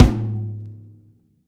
drum-hitfinish.ogg